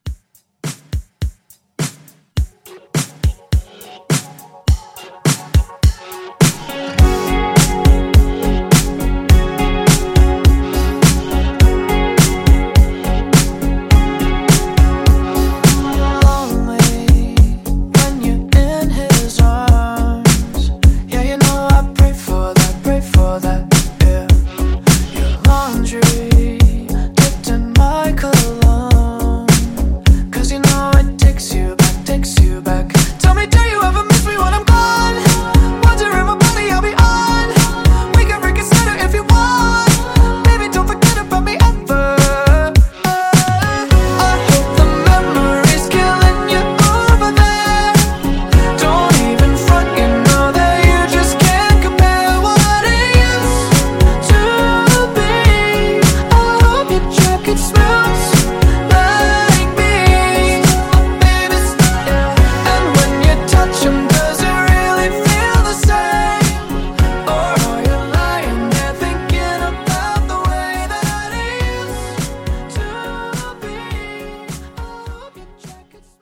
Genres: RE-DRUM , TOP40 Version: Clean BPM: 104 Time